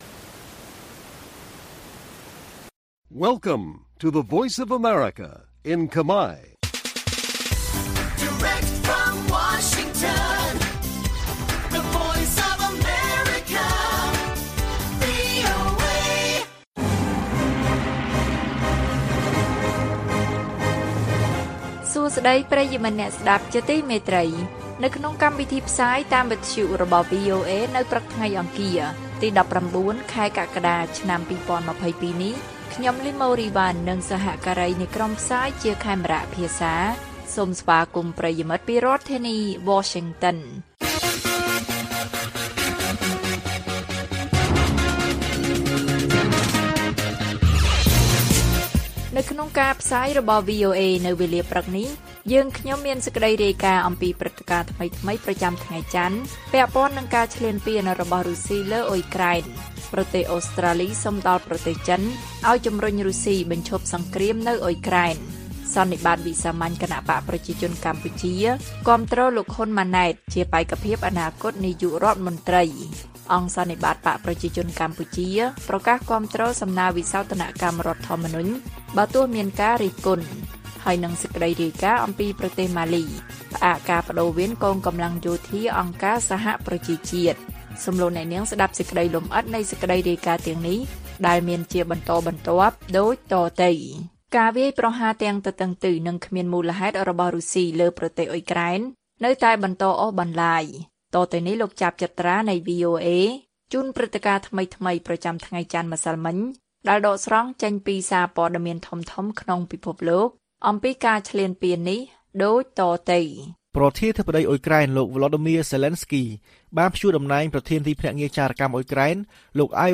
ព័ត៌មានពេលព្រឹក ១៩ កក្កដា៖ ប្រទេសអូស្ត្រាលីសុំដល់ប្រទេសចិនឱ្យជំរុញរុស្ស៊ីបញ្ឈប់សង្គ្រាមនៅអ៊ុយក្រែន